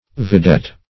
Vidette \Vi*dette"\, n. (Mil.)